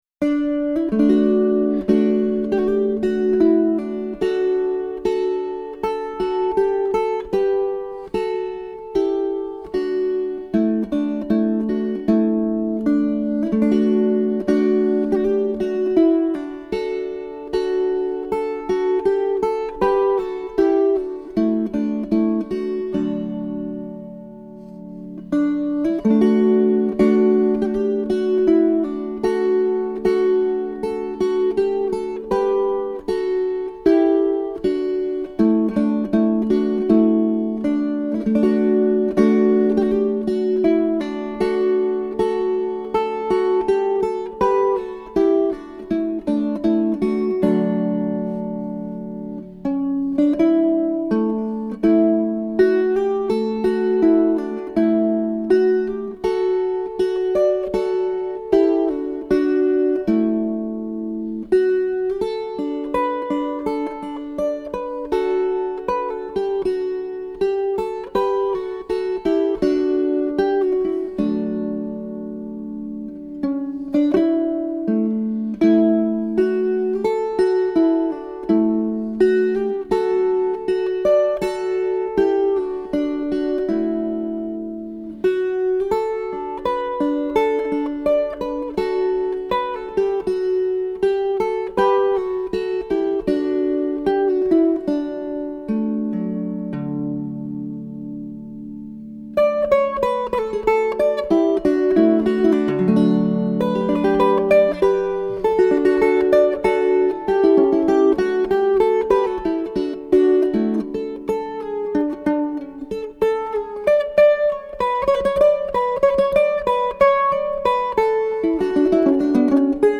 18th-century wire-strung guittar